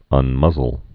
(ŭn-mŭzəl)